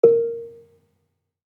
Gambang-A3-f.wav